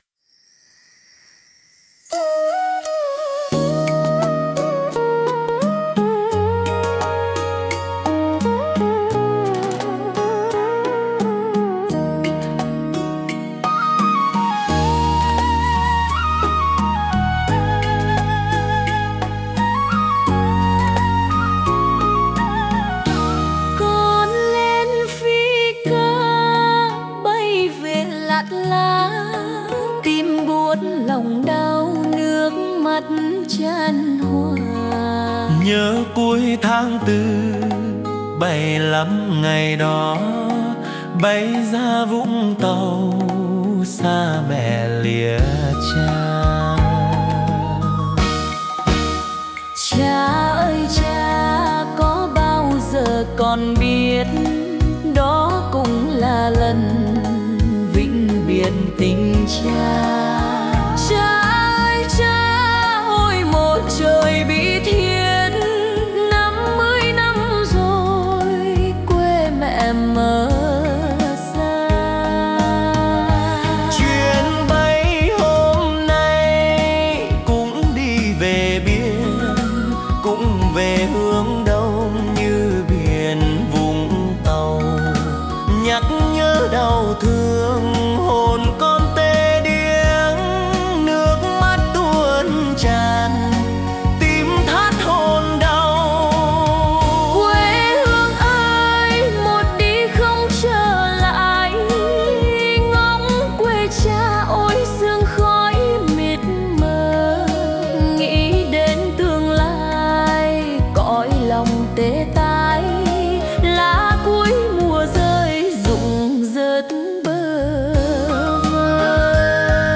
Nhạc Lời Việt